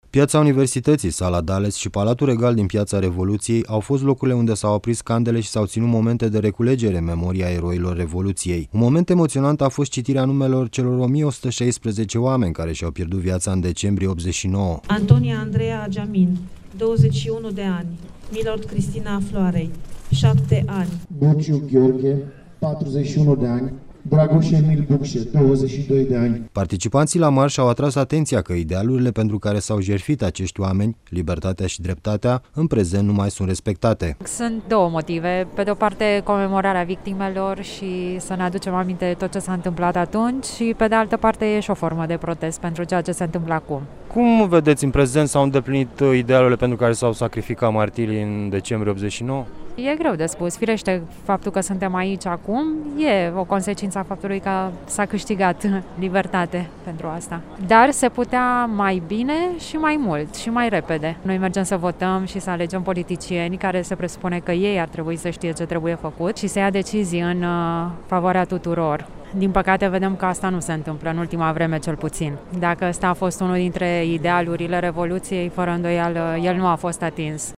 Manifestări comemorative la 29 de ani de la căderea comunismului au avut loc, ieri, în Bucureşti şi în mai multe oraşe din ţară. În Capitală, peste 2 mii de persoane au participat la un marş în memoria eroilor Revoluţiei, dar şi pentru a protesta faţă de actuala guvernare.